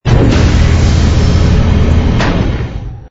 door_small_open.wav